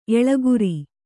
♪ eḷaguri